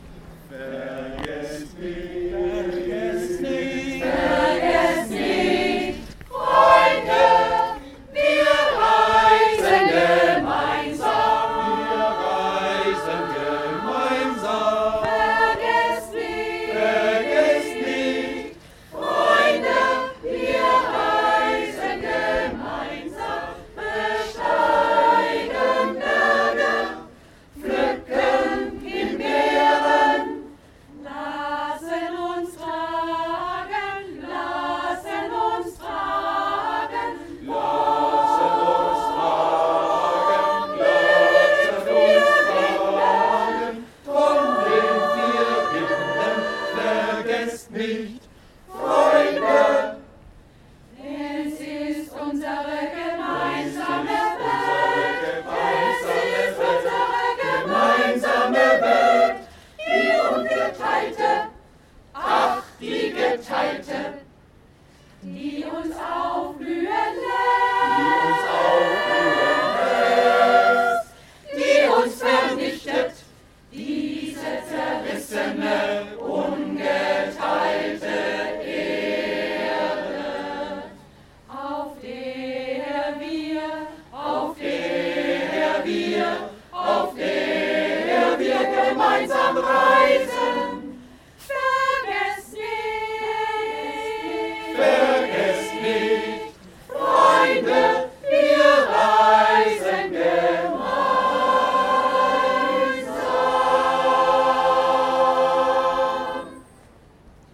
Unten zu finden sind die mit-geschnittenen Audios, etwas Stimmung und ein Lied des Ernst-Bloch-Chor und Bilder vom 20.10.2018
BLOCH CHOR live